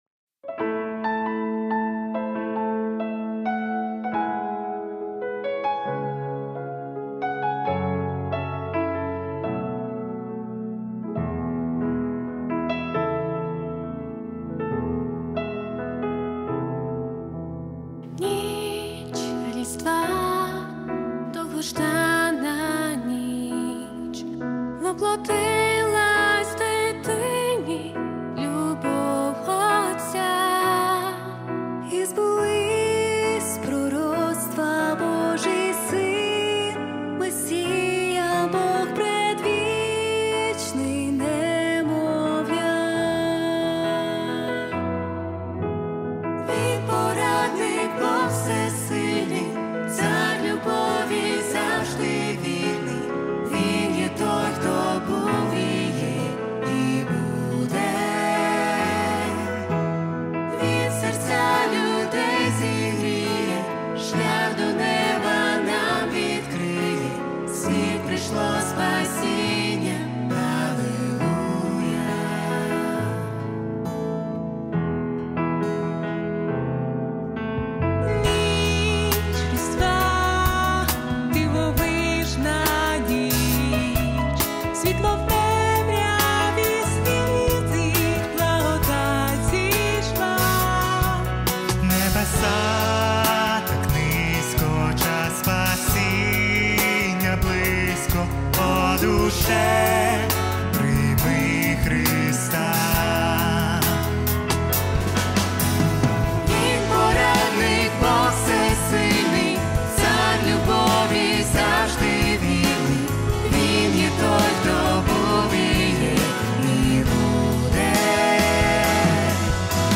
85 просмотров 88 прослушиваний 7 скачиваний BPM: 136